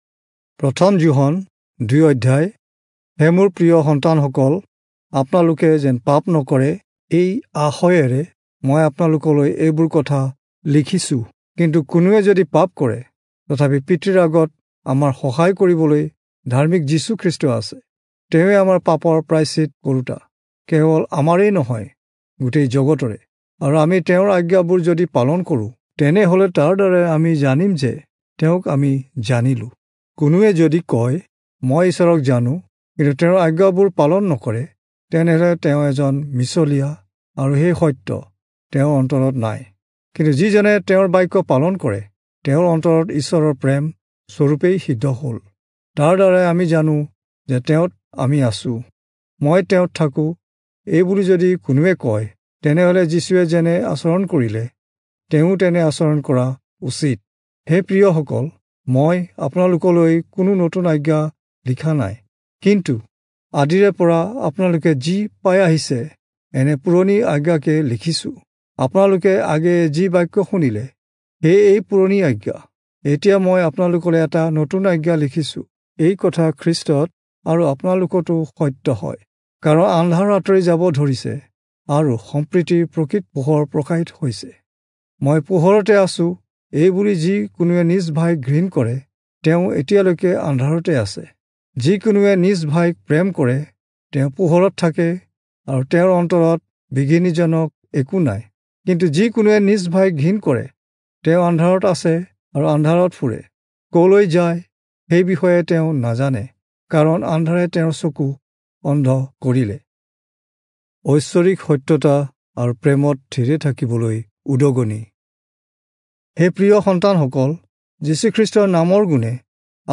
Assamese Audio Bible - 1-John 5 in Asv bible version